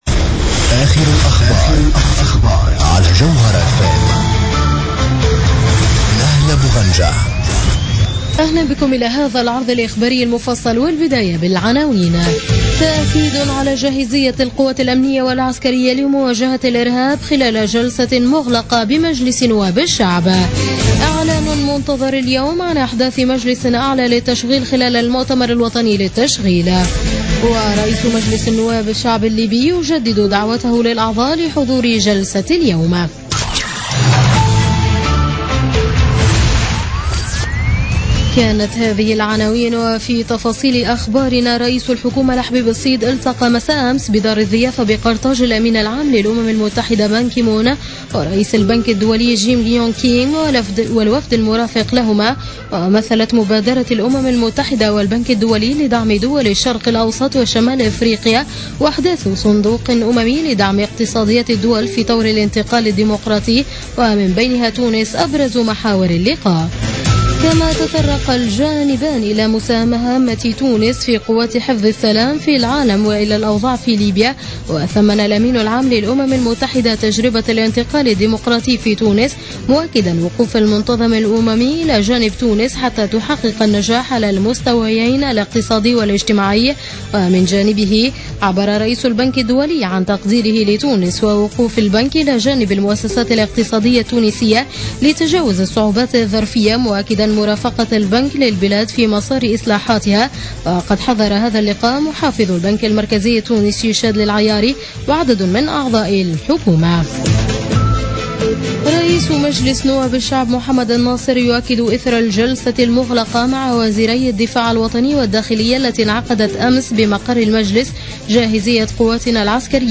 نشرة أخبار منتصف الليل ليوم الثلاثاء 29 مارس 2016